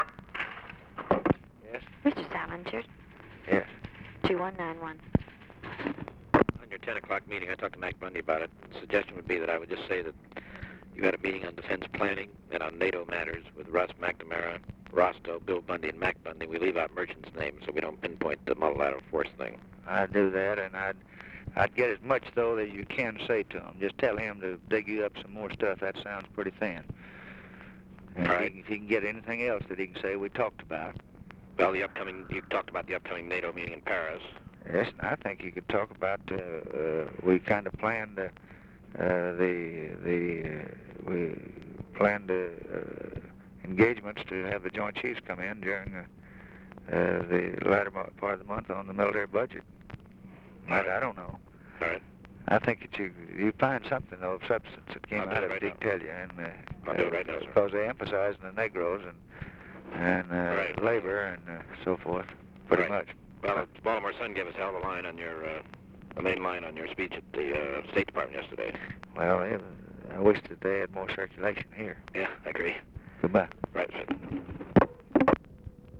Conversation with PIERRE SALINGER, December 6, 1963
Secret White House Tapes